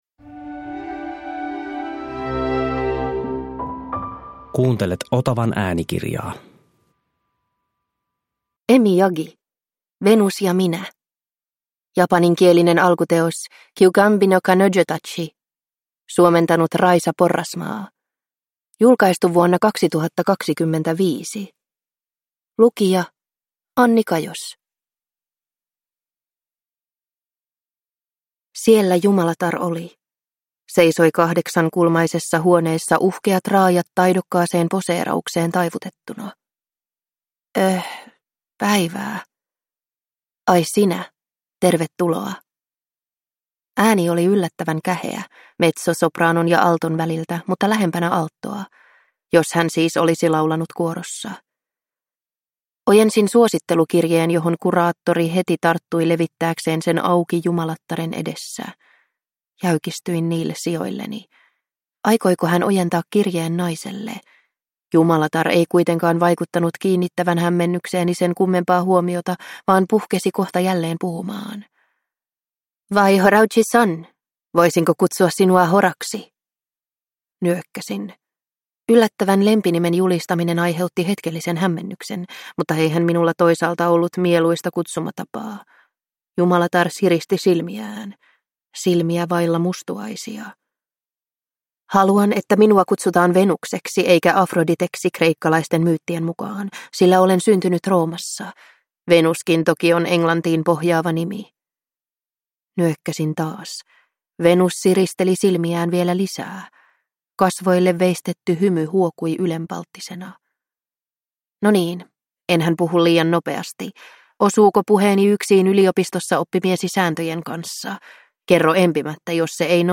Venus ja minä – Ljudbok